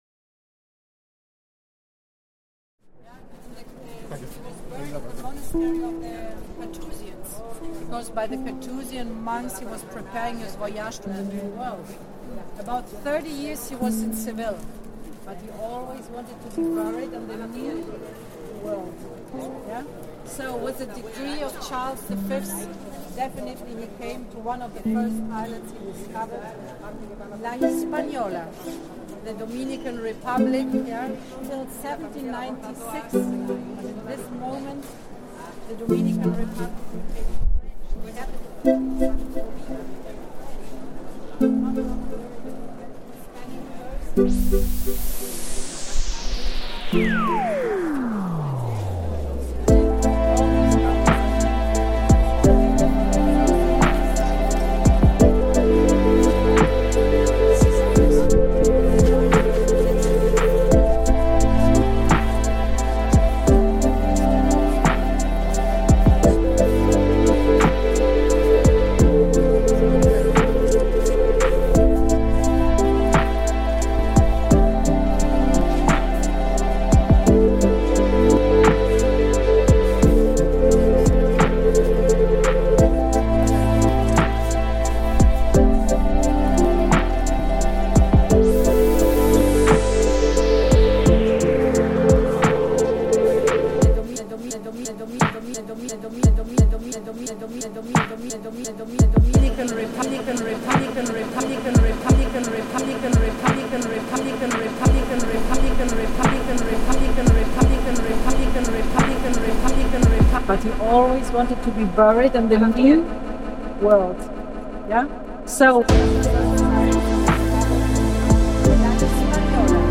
The organ is in the recording as part of a wedding ceremony, and you can really imagine what its like to be in Seville in the cathedral.
——————— This sound is part of the Sonic Heritage project, exploring the sounds of the world’s most famous sights.